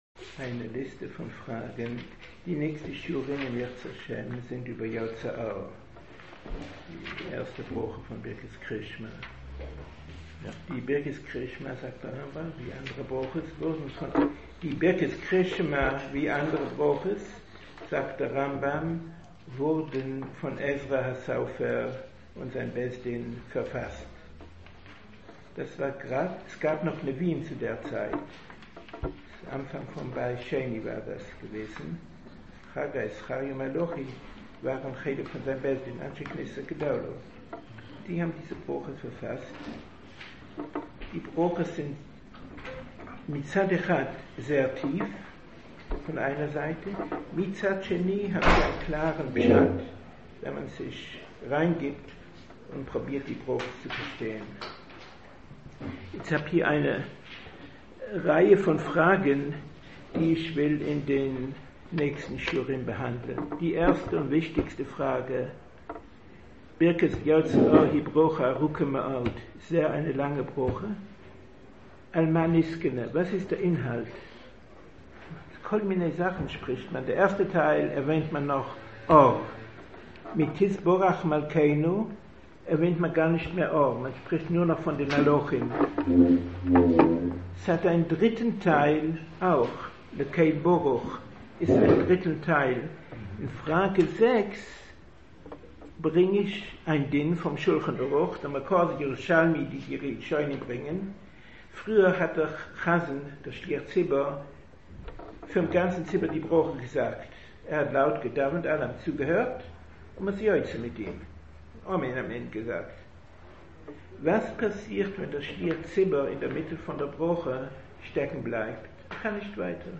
Schiur 08.05.2017 טעמים לאמירת פסוקי דזמראFortsetzung des Schiurs vom 01.05.2017